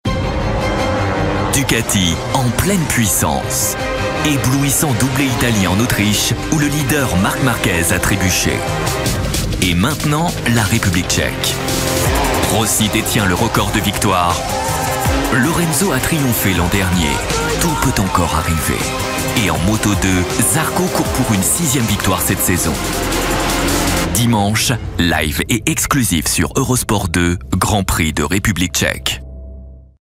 EUROSPORT evenement - Comédien voix off
Genre : voix off.